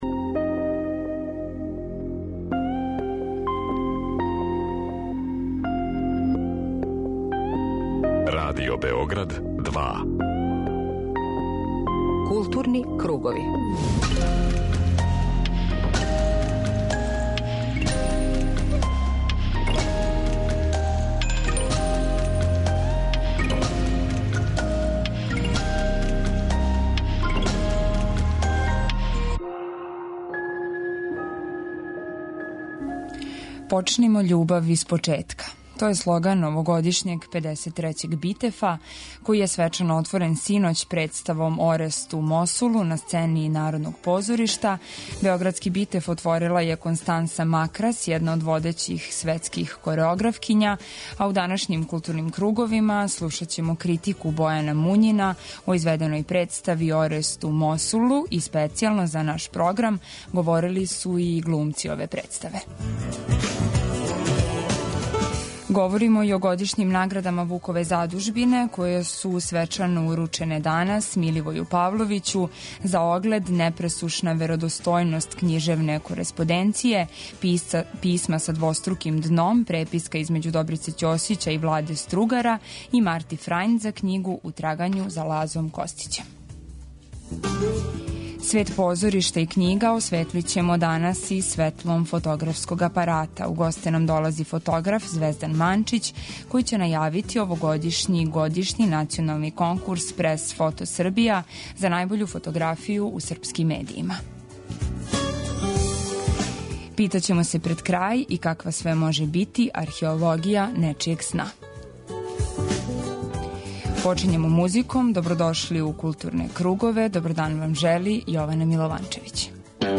Дневни магазин културе Радио Београда 2